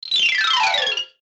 MG_sfx_vine_game_fall.ogg